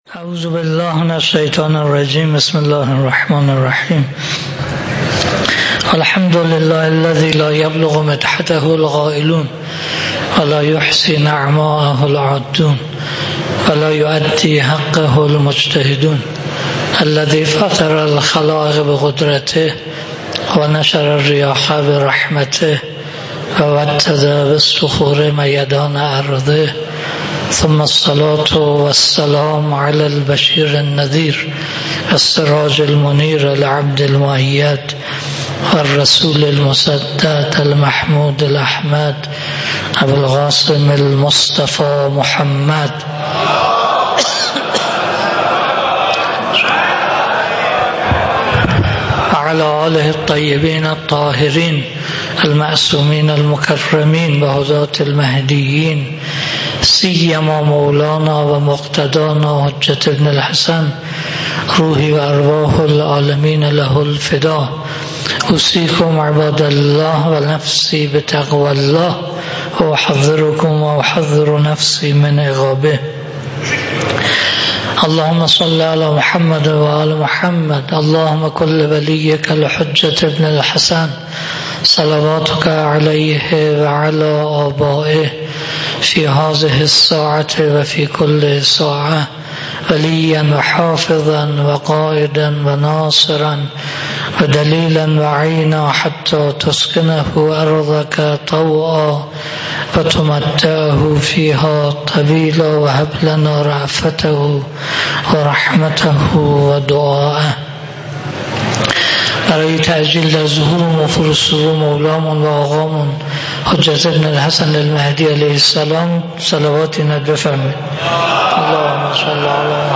خطبه-اول-3.mp3